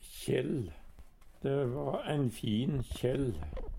kjell - Numedalsmål (en-US)
kjell eit plagg som ligg oppå skinnfellen Eintal ubunde Eintal bunde Fleirtal ubunde Fleirtal bunde Eksempel på bruk Det va ein fin kjell. Høyr på uttala Ordklasse: Substantiv hankjønn Kategori: Tekstilhandverk Attende til søk